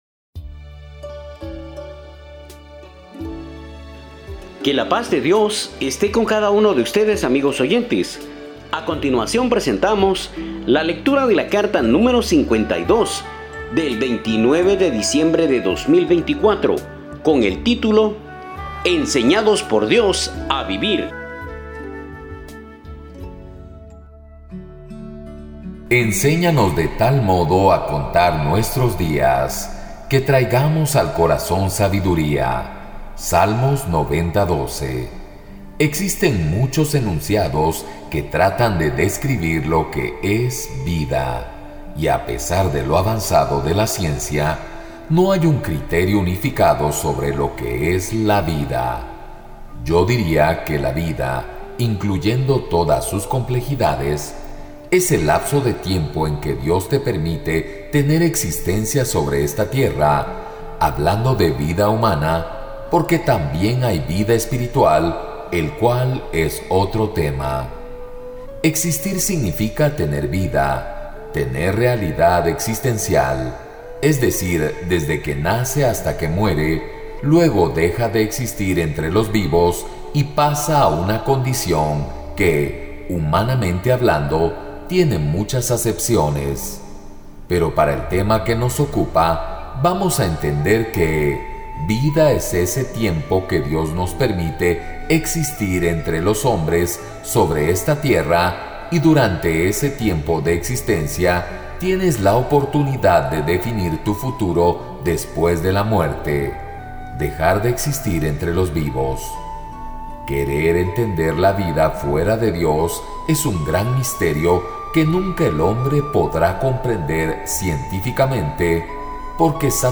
Audio de la Carta Descargar